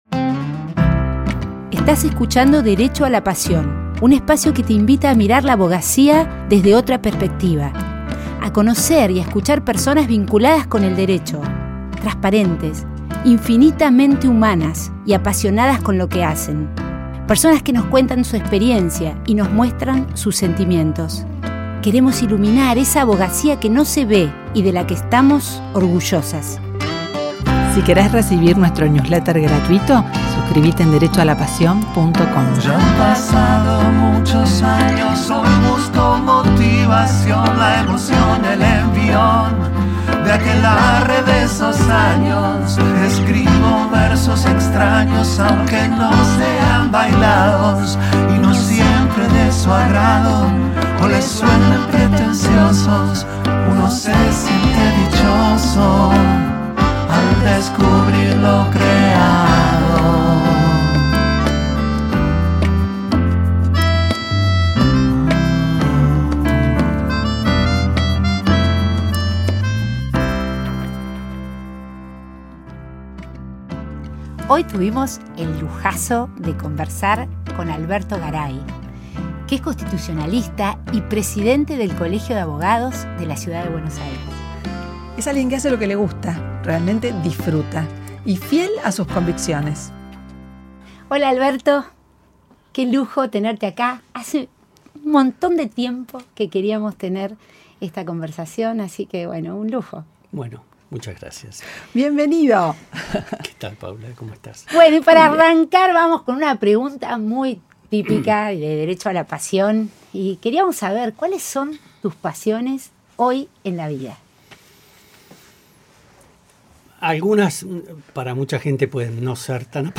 Imposible que no termines cantando con él.